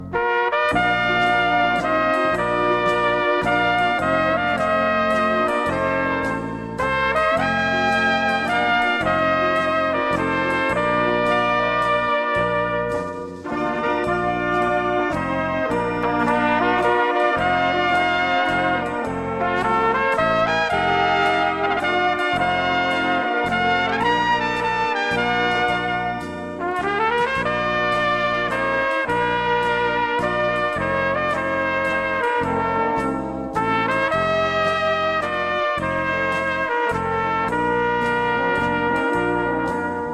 Gattung: Solo für 2 Trompeten und Blasorchester
Besetzung: Blasorchester
Solo für 2 Trompeten mit Blasorchester.